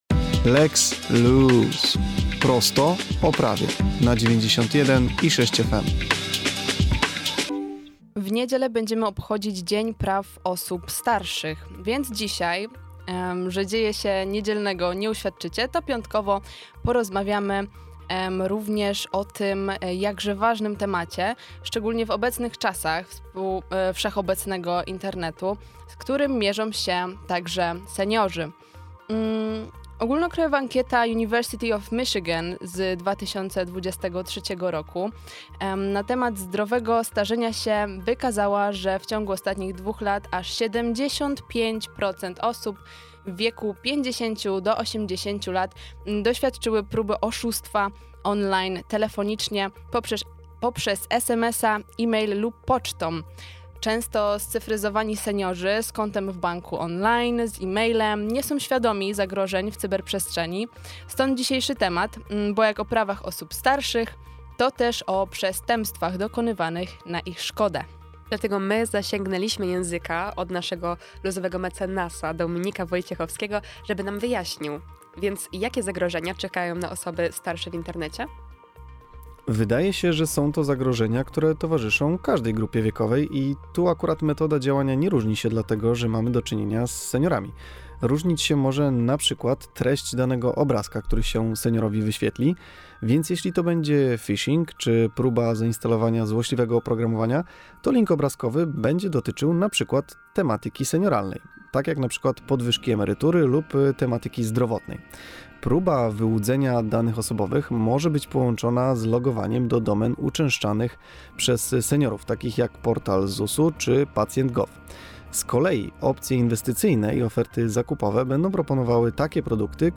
Całość rozmowy z audycji Dzieje Się! znajdziecie poniżej: